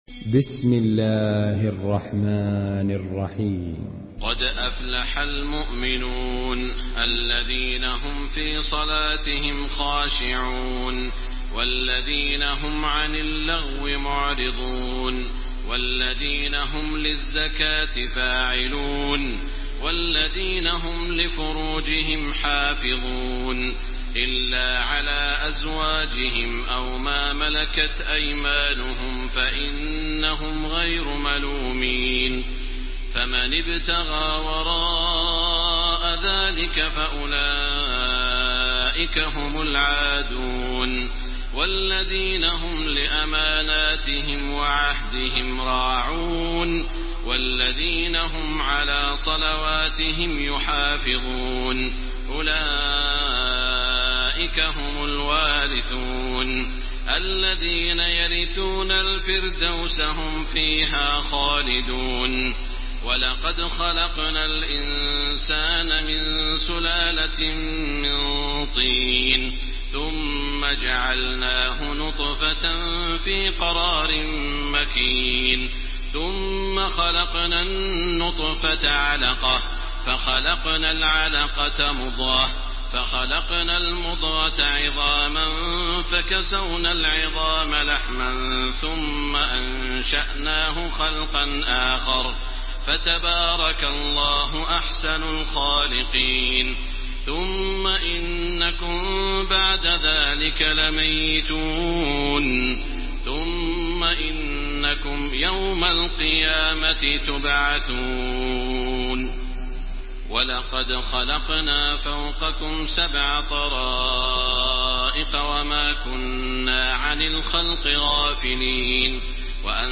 Surah المؤمنون MP3 by تراويح الحرم المكي 1430 in حفص عن عاصم narration.
مرتل